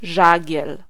Ääntäminen
France: IPA: /vwal/